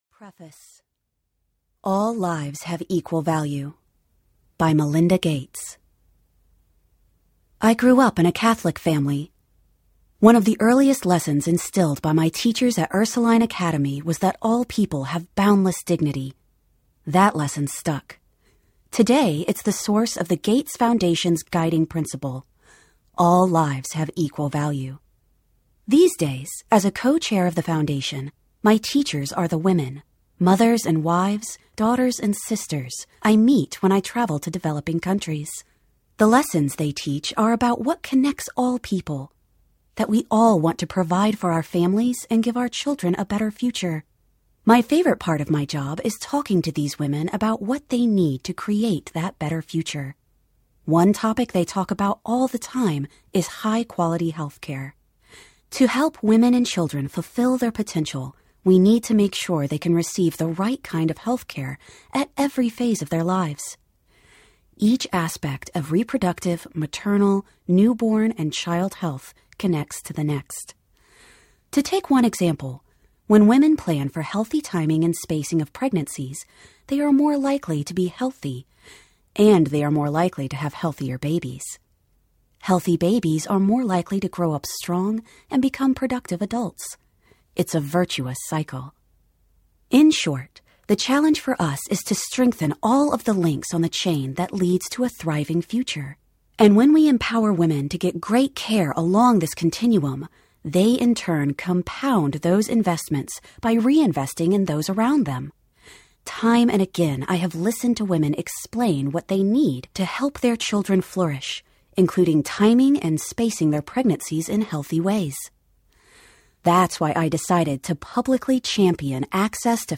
The Mother and Child Project Audiobook
Narrator
Various Artists
6.7 Hrs. – Unabridged